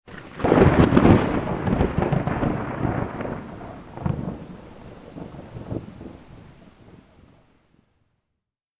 دانلود صدای رعدو برق 25 از ساعد نیوز با لینک مستقیم و کیفیت بالا
جلوه های صوتی
برچسب: دانلود آهنگ های افکت صوتی طبیعت و محیط دانلود آلبوم صدای رعد و برق از افکت صوتی طبیعت و محیط